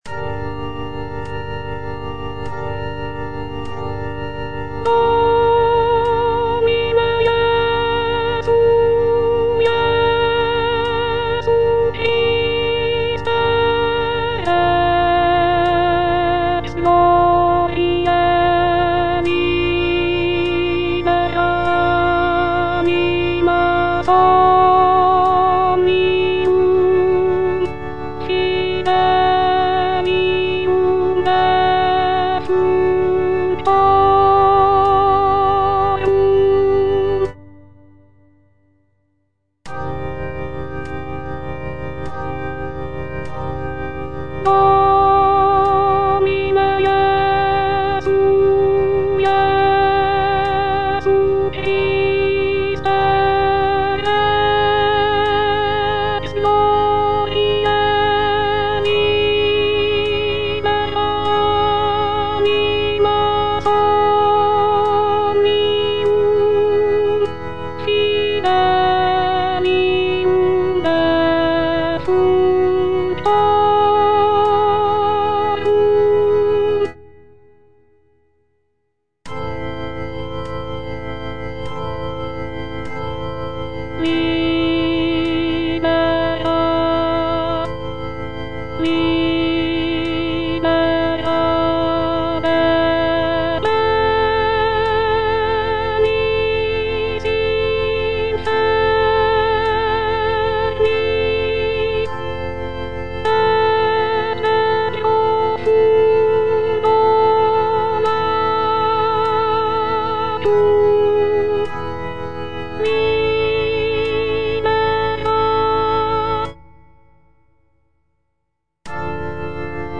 F. VON SUPPÈ - MISSA PRO DEFUNCTIS/REQUIEM Domine Jesu - Alto (Voice with metronome) Ads stop: auto-stop